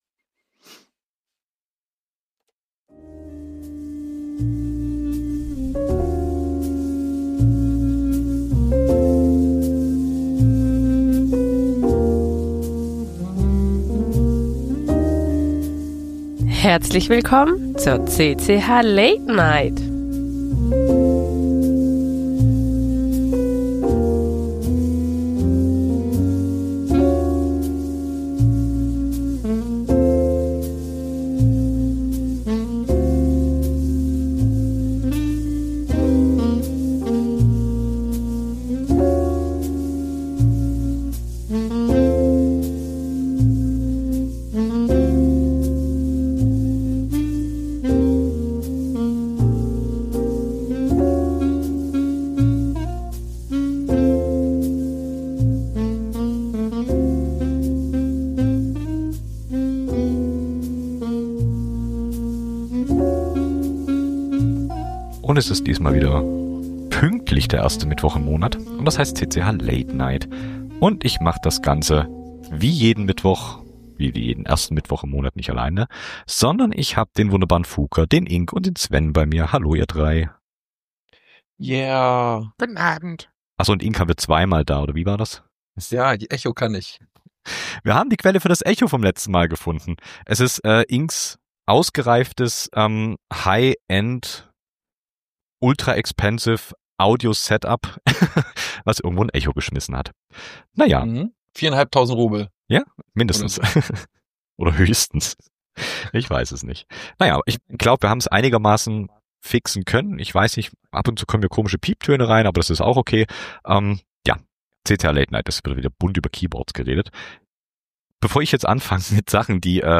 Die CCH! Late Night! ist eure monatliche Live-Keyboard-Late-Night-Show.